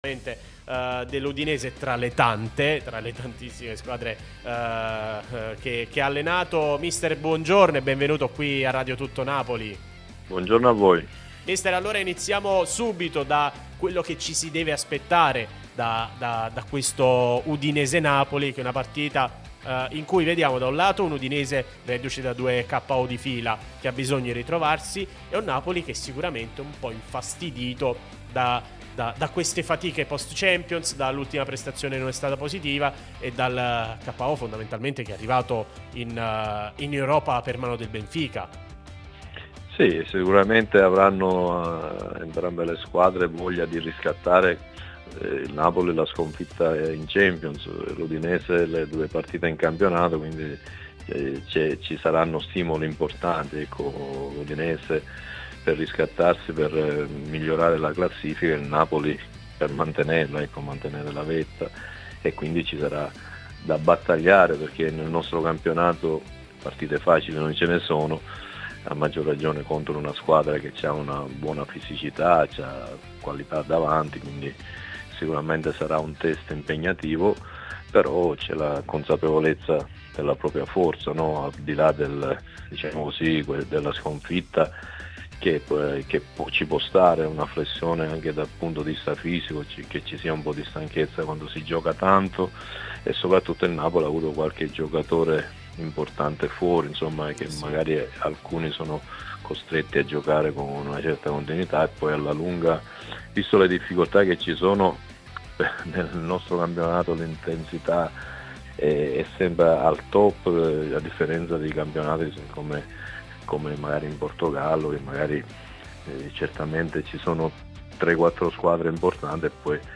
Pasquale Marino, ex allenatore dell'Udinese, è intervenuto nel corso de Il Bar di Tuttonapoli, trasmissione sulla nostra Radio Tutto Napoli, prima radio tematica sul Napoli, in onda tutto il giorno, che puoi ascoltare/vedere qui sul sito o sulle app (qui per Iphone/Ipad o qui per Android).